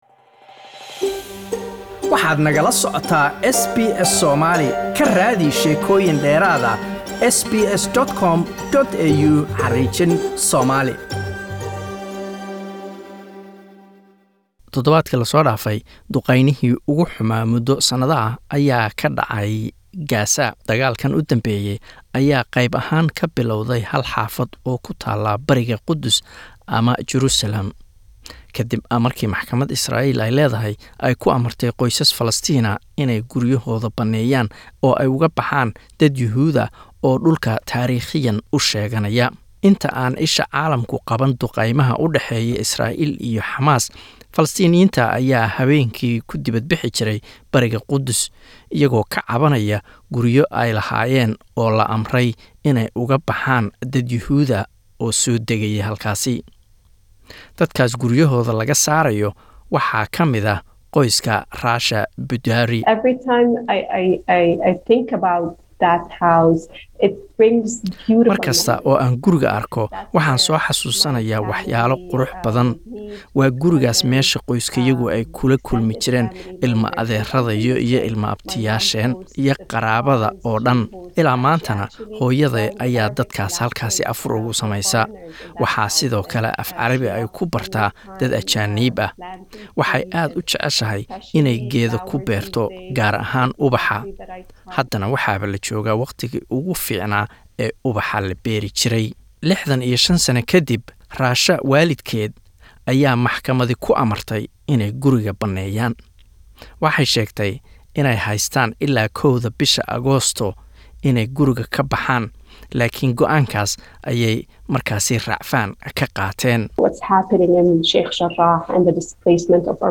Iyadoo ay wali socdaan colaada u dhaxaysa Isreal iyo Falatiiniyiinta ayaan waxaan ku eegaynaa warbixintan taariiqda dheer ee colaadan.